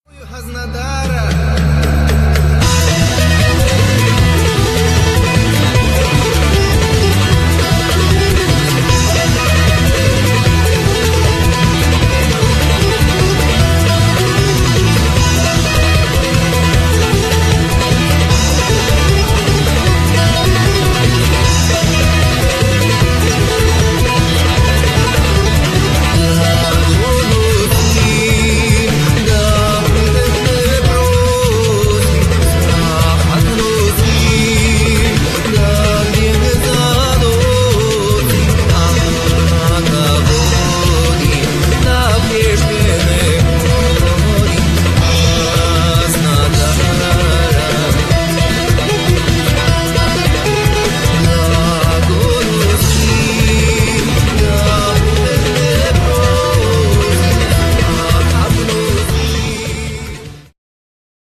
śpiew vocal, tambura